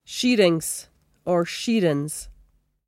[shEER-eens or shEER-ens]